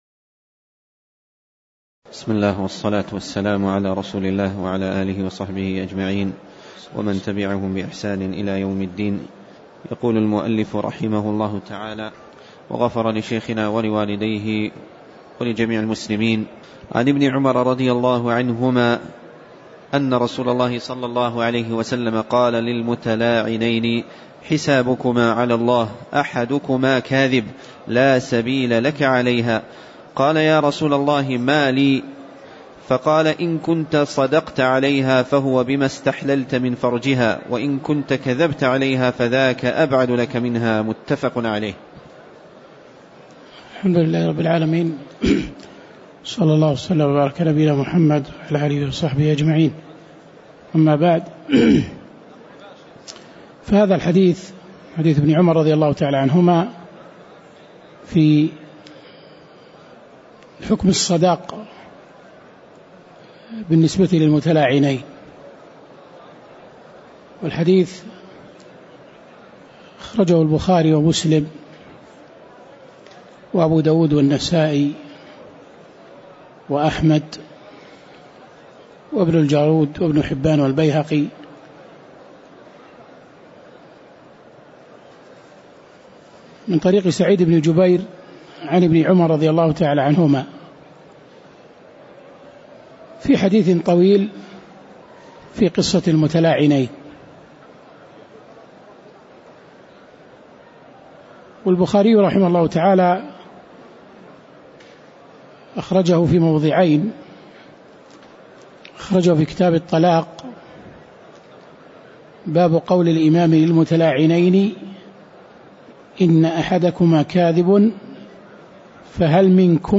تاريخ النشر ٢٨ رجب ١٤٣٨ هـ المكان: المسجد النبوي الشيخ